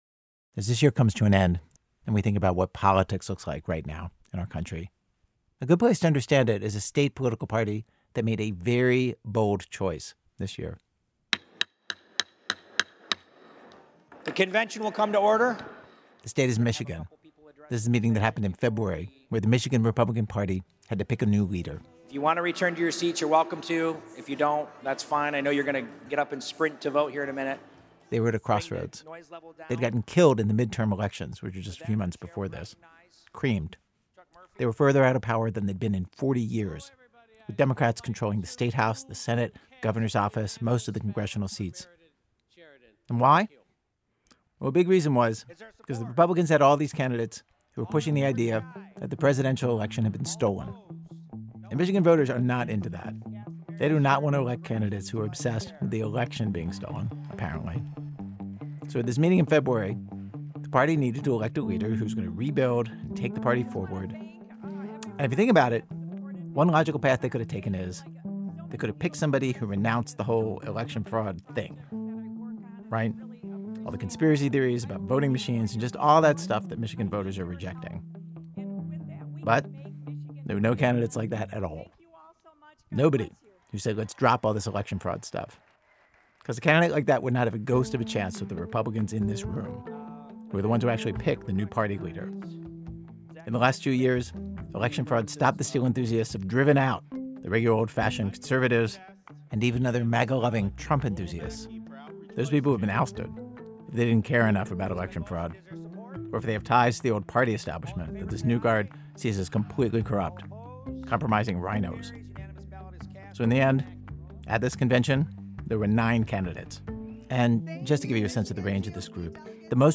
Note: The internet version of this episode contains un-beeped curse words.